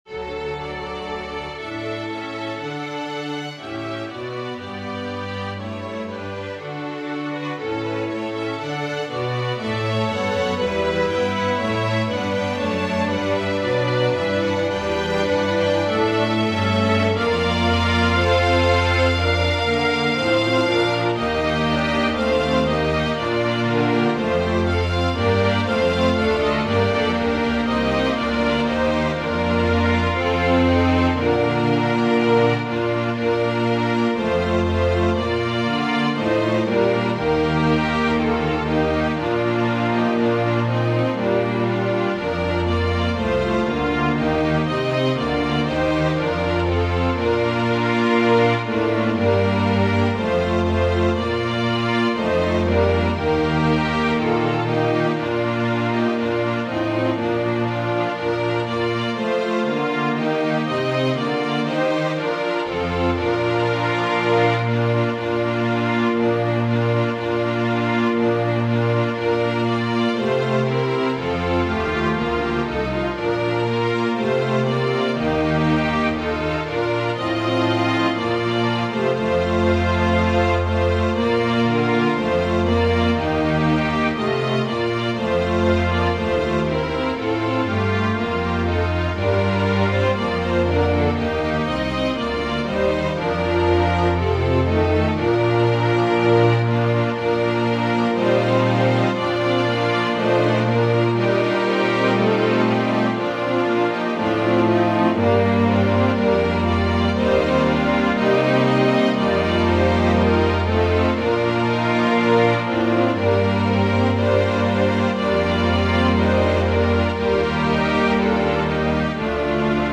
Voicing/Instrumentation: Organ/Organ Accompaniment We also have other 31 arrangements of " The Spirit of God ".